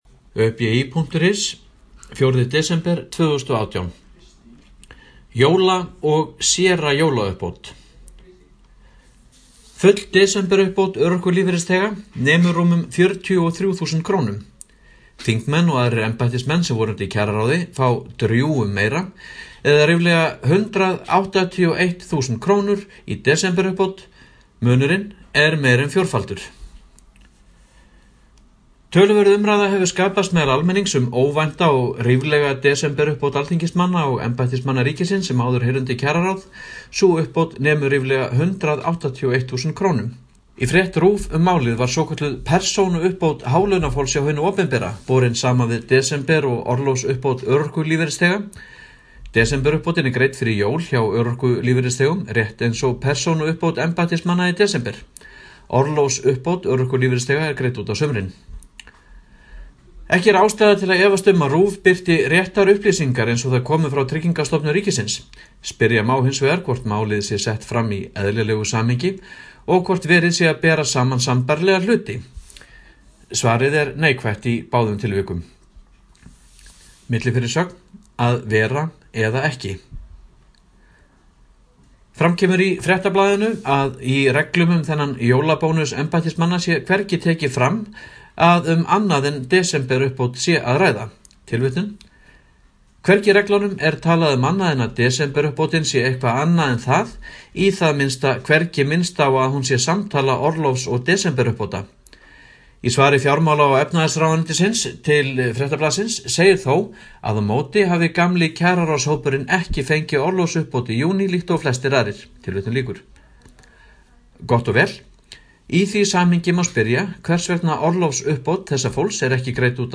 Hljóðritað hjá Hljóðbók slf. í desember 2018.